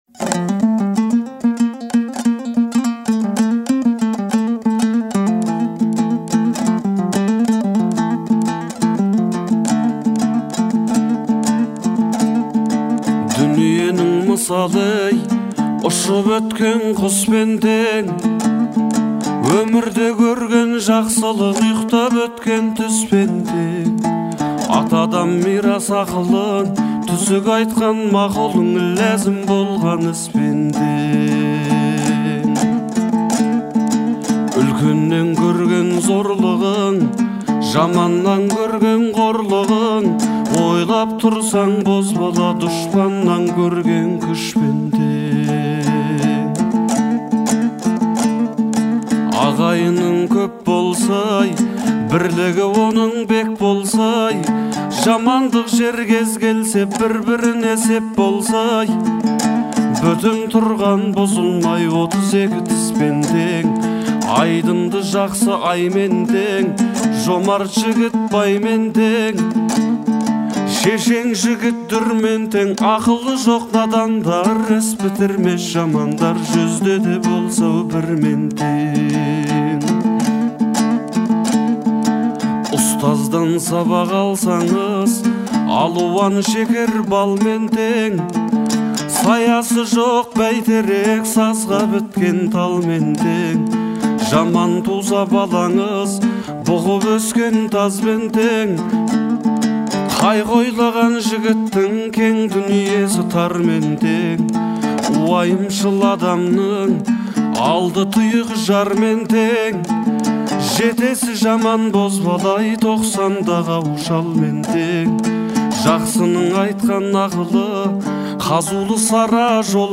обладая мощным и выразительным голосом